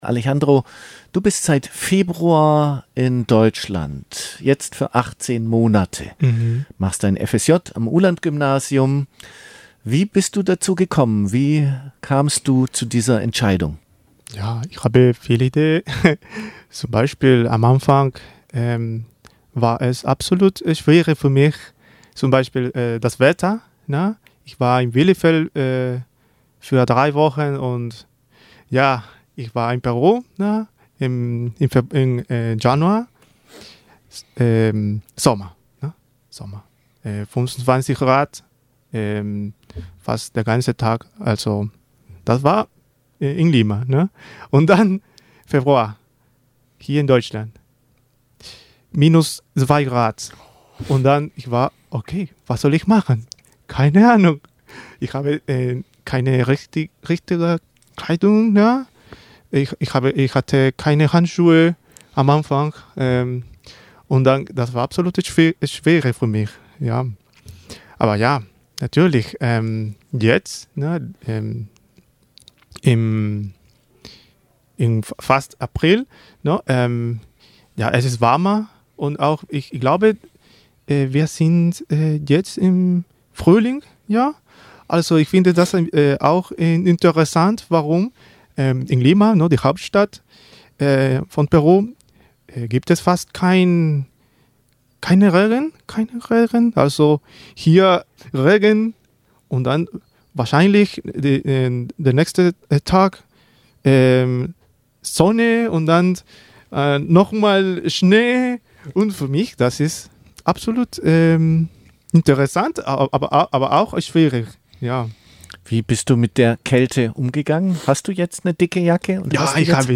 Im ersten Interview auf Deutsch erzählt er vom Schock der Wetterumstellung, seiner Leidenschaft des Geige spielens und natürlich auch von seinen Aufgaben in der Schule.